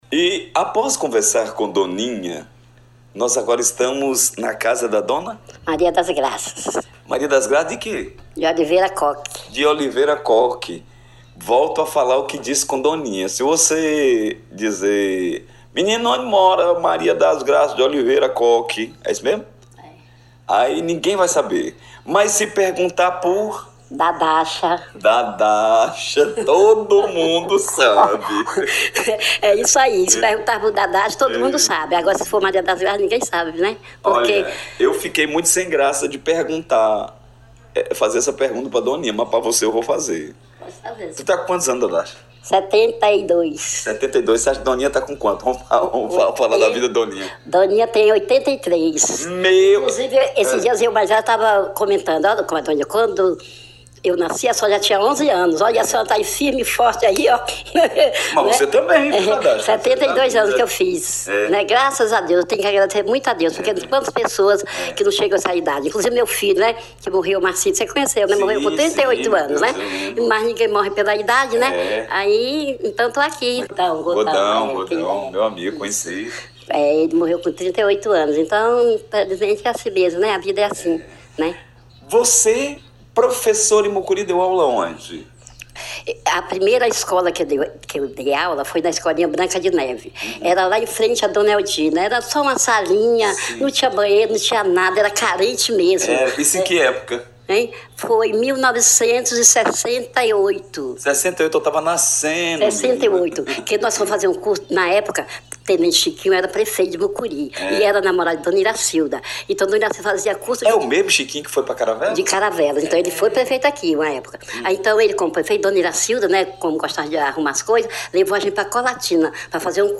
Plantando o Futuro – Entrevista